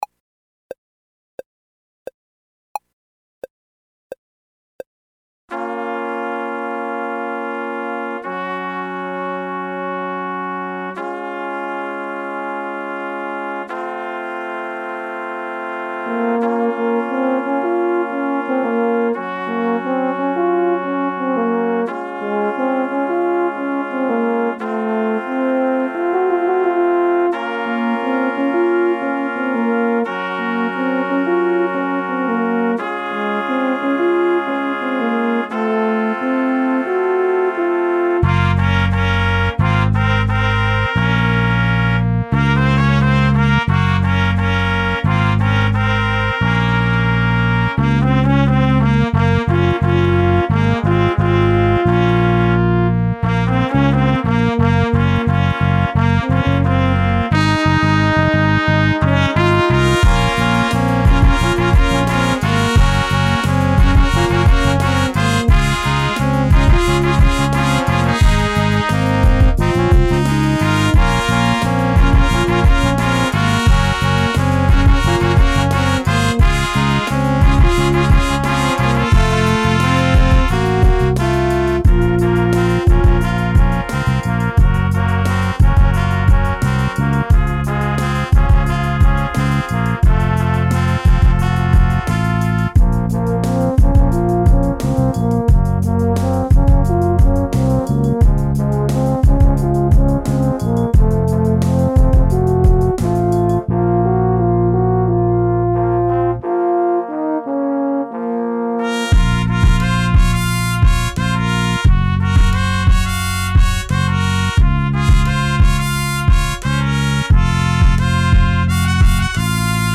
Trp 1 Trp 2 Pos 1 Pos 2 Horn BDrum Lyr Sax